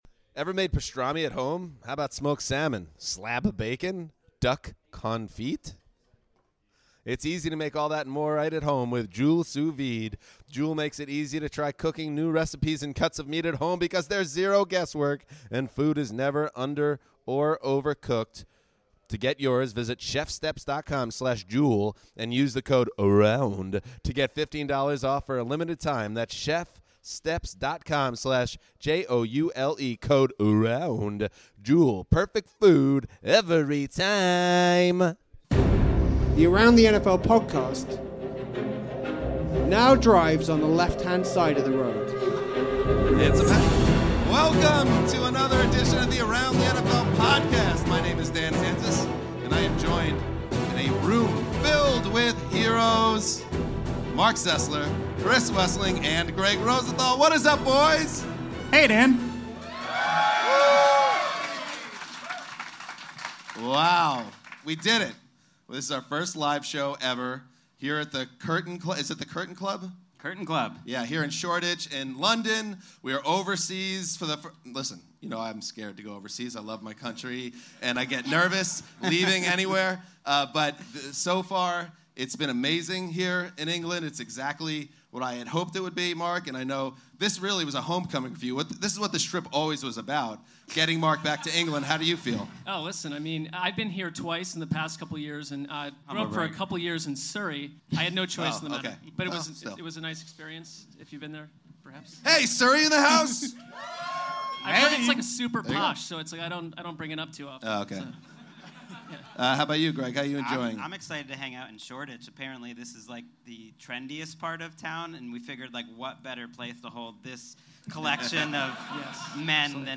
ATN Live from The Curtain Club in London